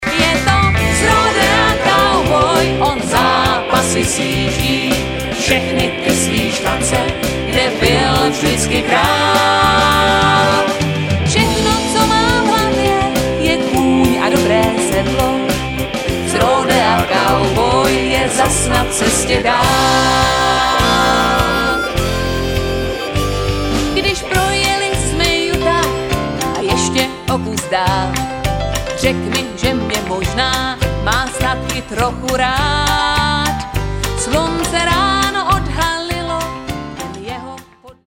Sólový zpěv